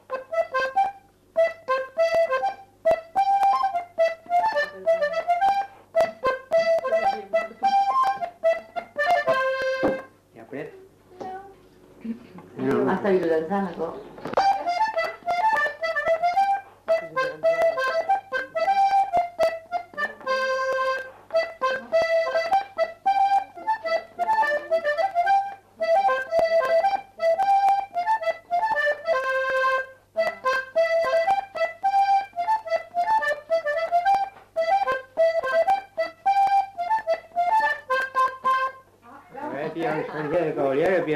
Aire culturelle : Agenais
Lieu : Galapian
Genre : morceau instrumental
Instrument de musique : accordéon diatonique
Danse : quadrille